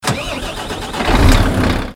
engine_start.mp3